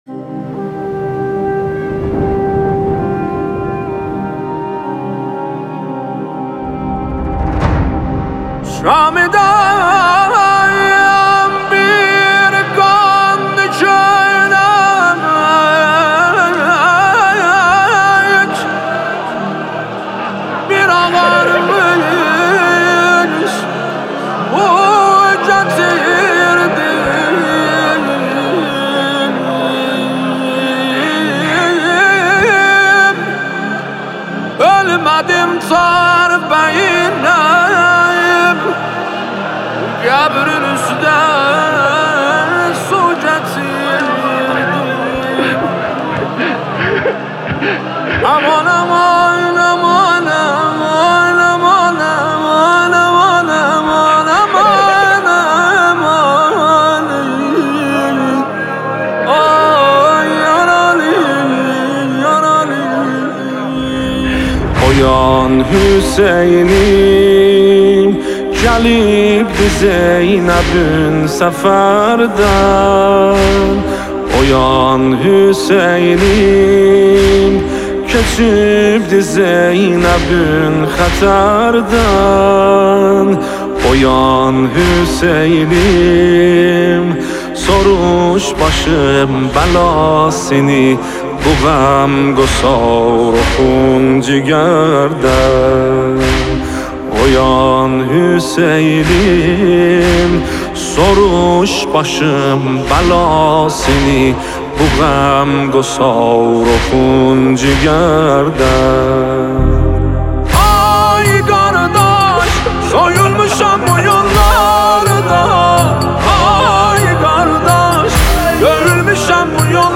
نوحه جدید اربعین